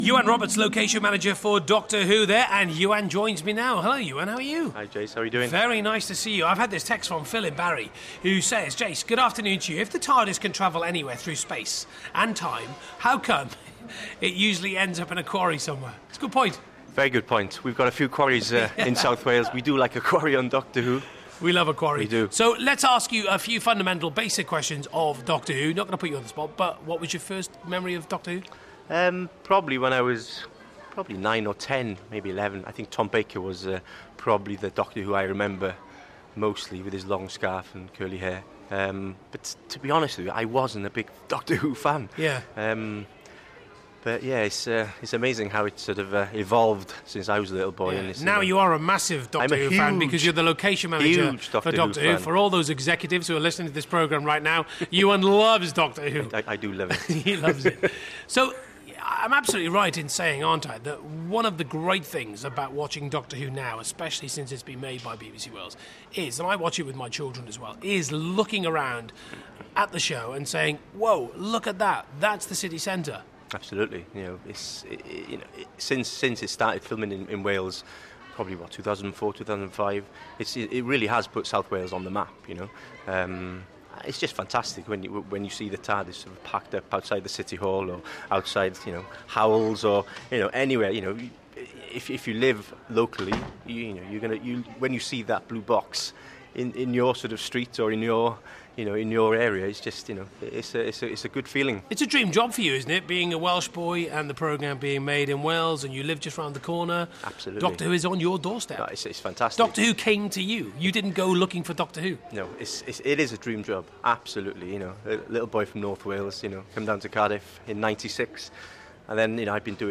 On Radio Wales Doctor Who Day, Jason Mohammad talks live